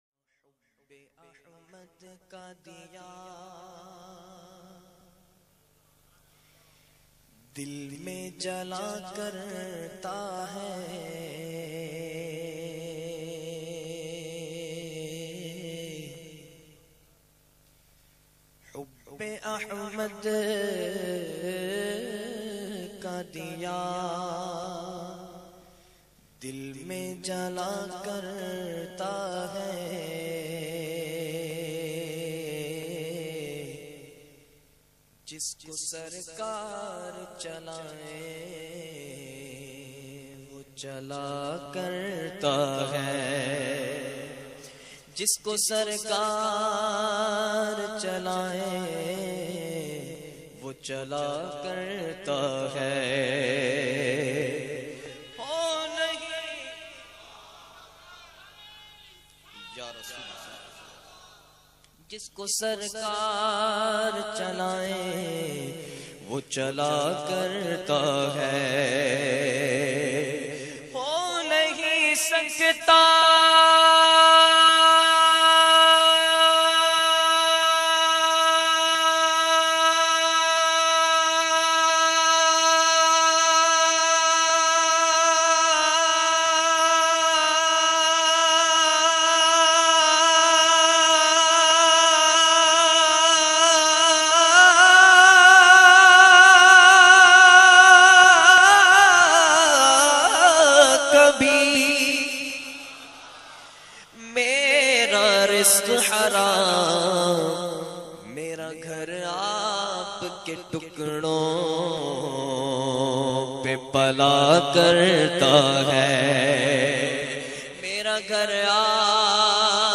URDU NAAT